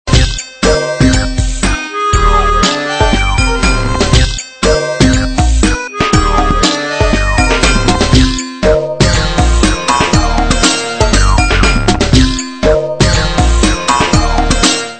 illustrations sonores